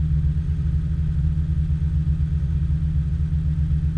rr3-assets/files/.depot/audio/Vehicles/v10_01/v10_01_idle.wav
v10_01_idle.wav